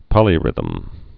(pŏlē-rĭthəm)